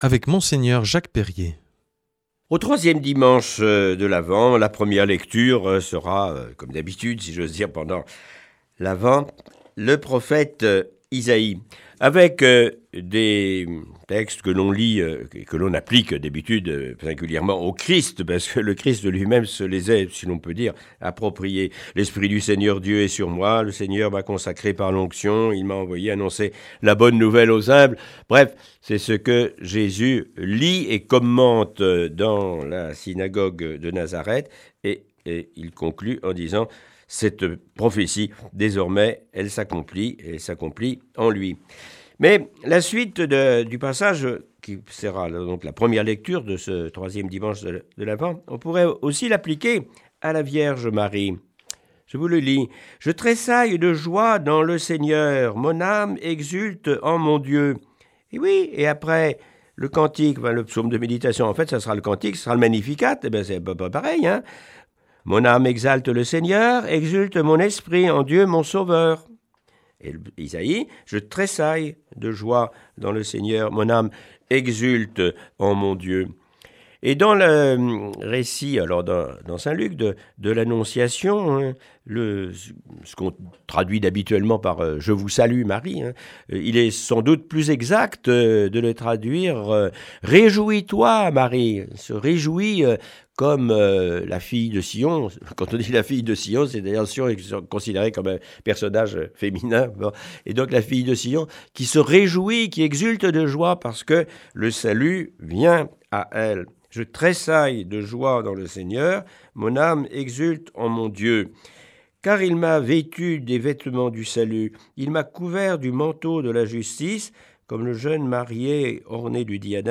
Aujourd’hui avec Mgr Jacques Perrier, évèque émérite de Tarbes et Lourdes.